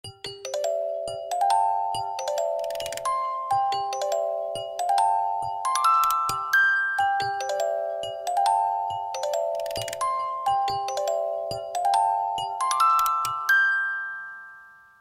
lg-clockwork_24589.mp3